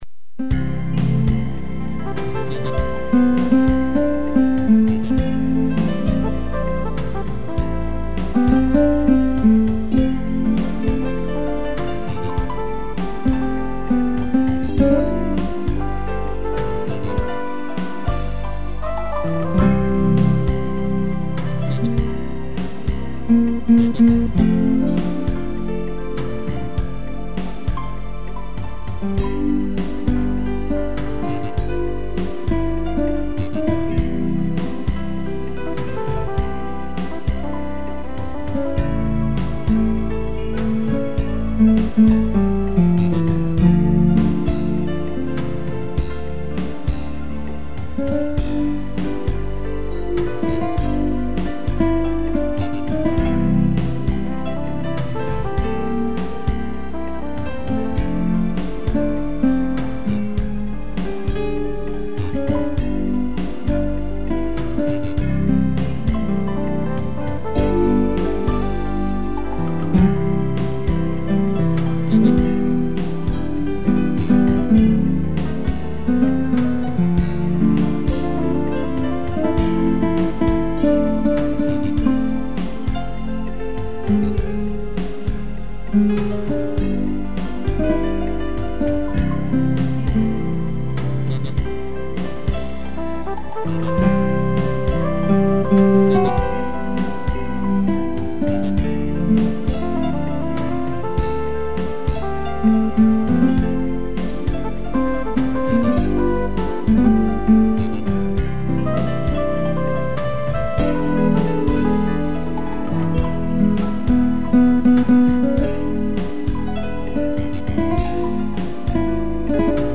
piano e flugel horn
(instrumental)